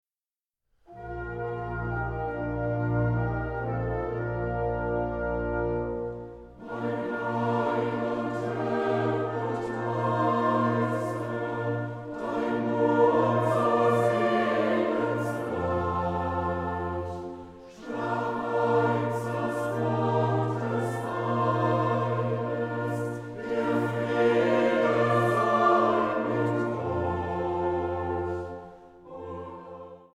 Chor, Orchester, Orgel